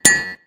neverlose sound Meme Sound Effect